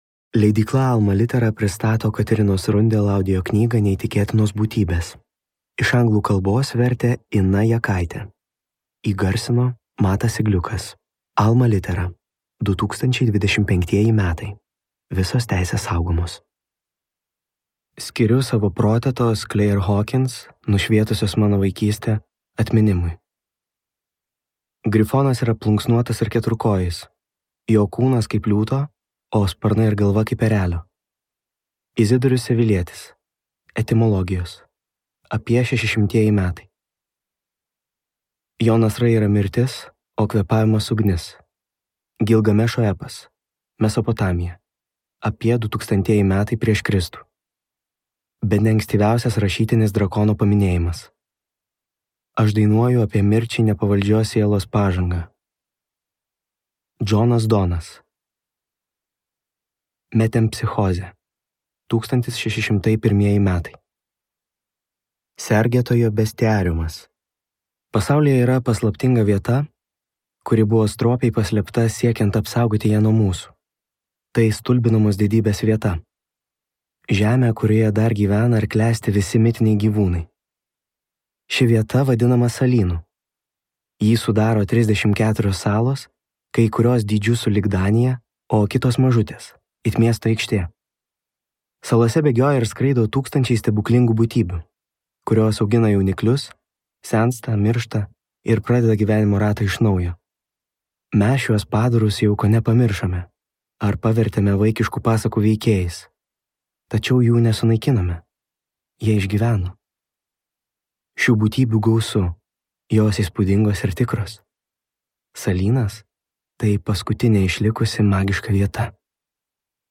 Neįtikėtinos būtybės | Audioknygos | baltos lankos